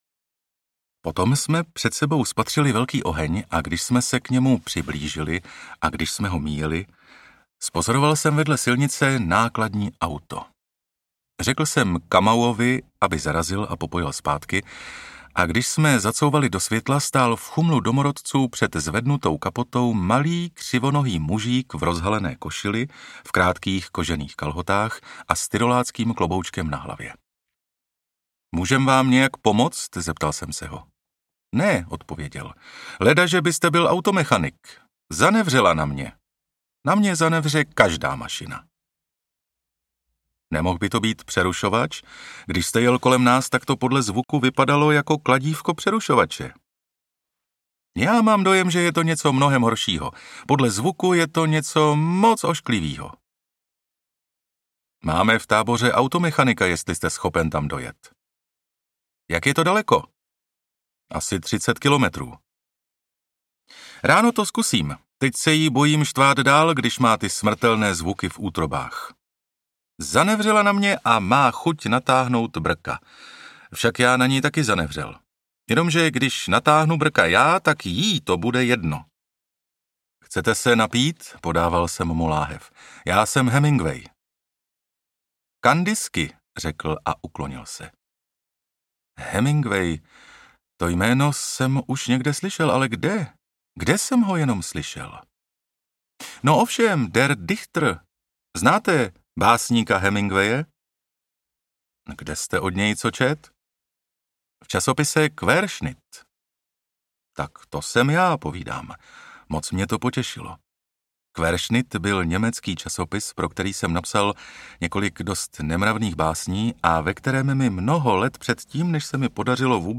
Zelené pahorky africké audiokniha
Ukázka z knihy
Mastering Soundguru.
Vyrobilo studio Soundguru.